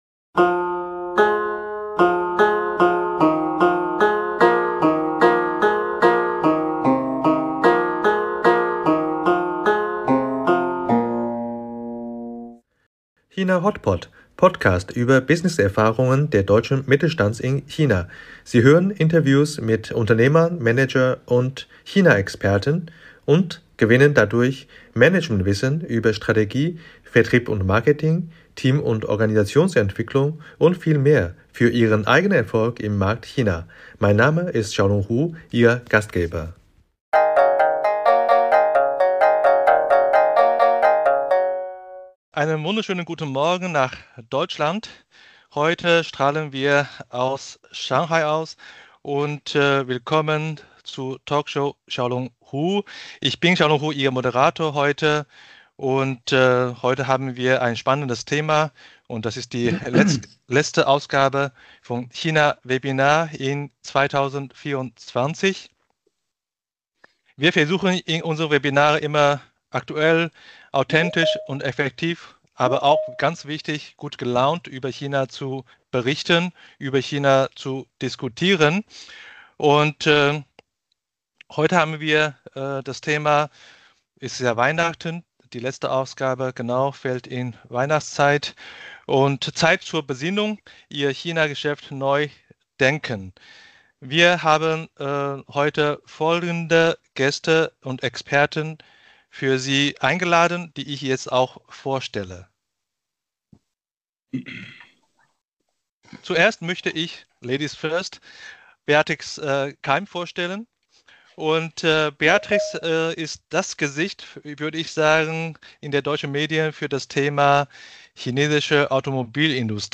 Live Aufnahme Talkshow